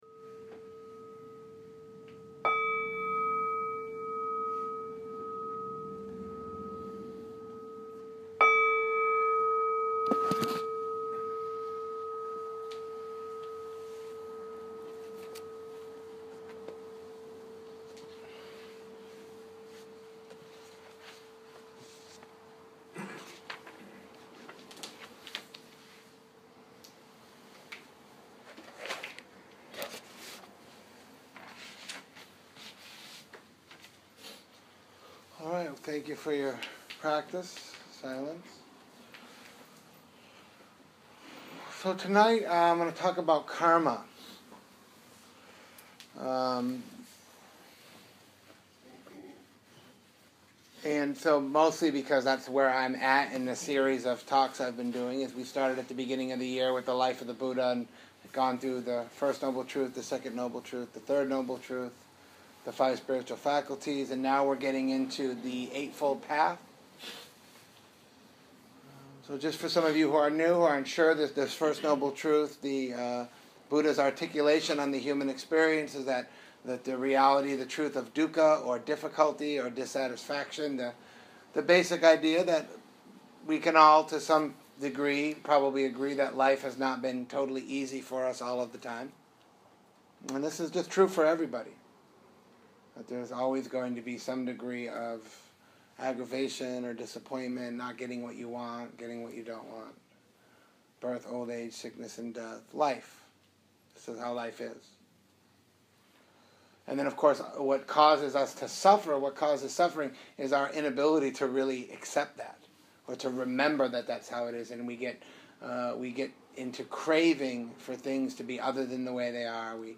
A talk deep from the vaults of Against the Stream Nashville. A talk on karma, as related to right view.